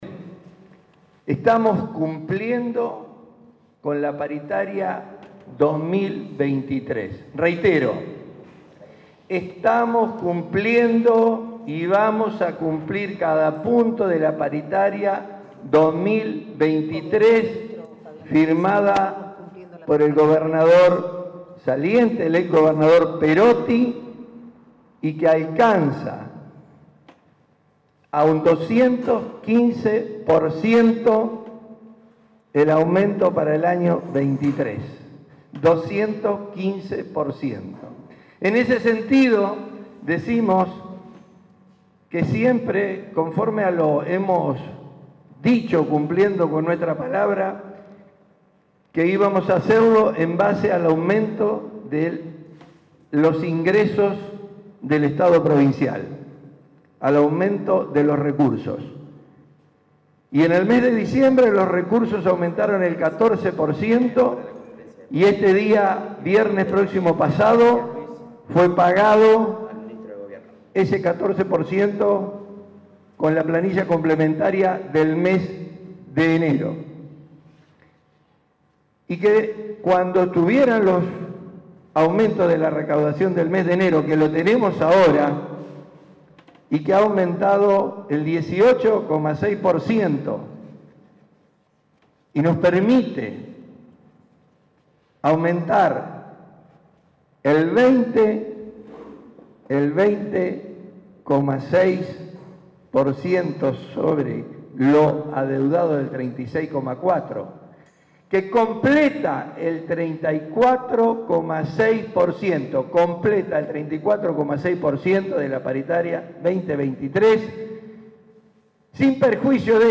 Las autoridades brindaron una conferencia de prensa en Casa de Gobierno donde ofrecieron detalles del esfuerzo económico que implica para el Estado afrontar este compromiso en un contexto de crisis: “Estamos cumpliendo con la Paritaria 2023 que alcanza un 215% de aumento -dijo Bastia-, siempre cumpliendo con nuestra palabra, que íbamos a hacerlo en base al aumento de ingresos de los recursos del Estado provincial”, y recordó que en diciembre “los recursos aumentaron el 14%, diferencia que ya fue abonada mediante planilla complementaria”.
Fabian Bastia - Pablo Olivares